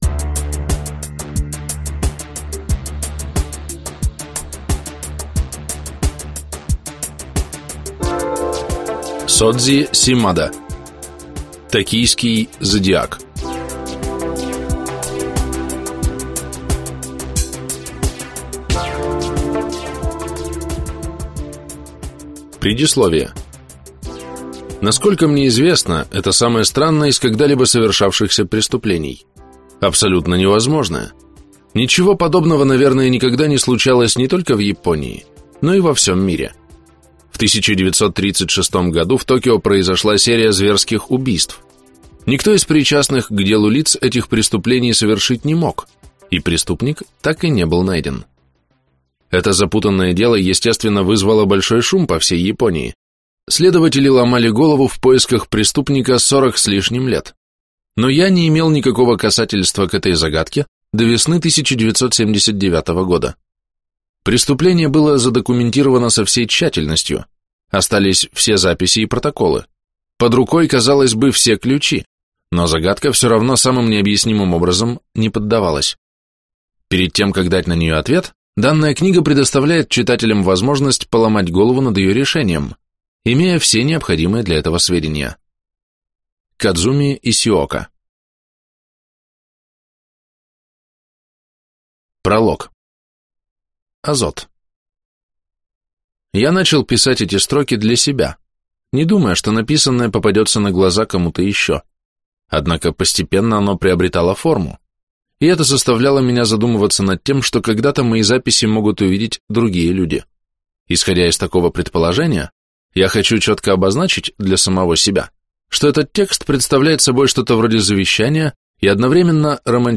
Аудиокнига Токийский Зодиак | Библиотека аудиокниг